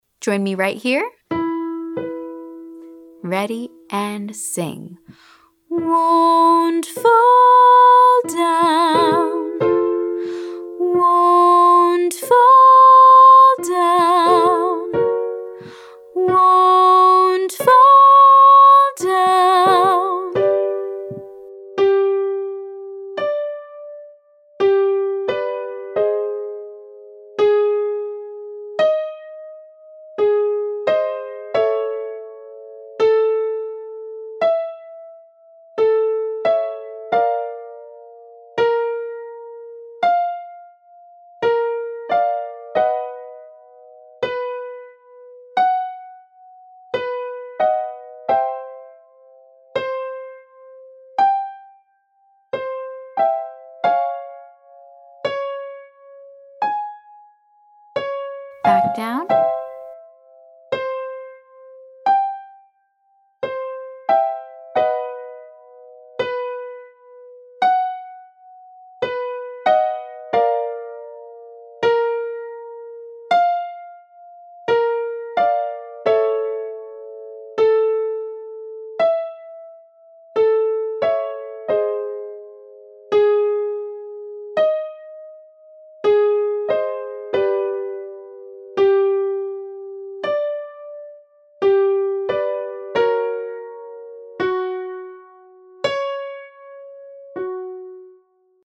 Day 7: High Head Voice Extension